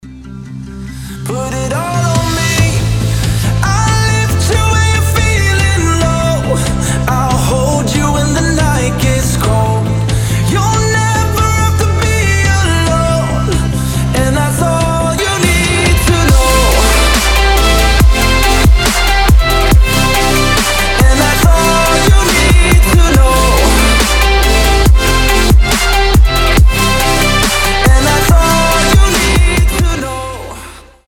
гитара
красивый мужской голос
Electronic
Melodic dubstep
романтичные
future bass
melodic future bass